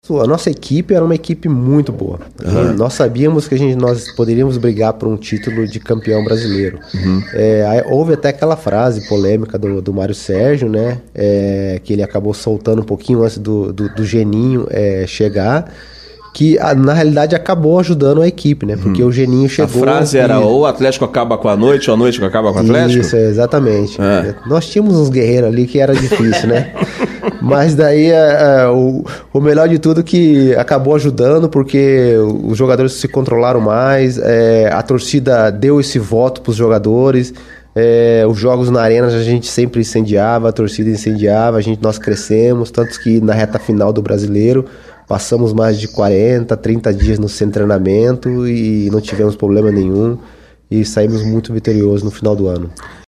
Entrevistado pela CBN, o volante Kleberson recordou a campanha, que teve dois treinadores: após o décimo jogo, saiu Mário Sérgio, entrou Geninho.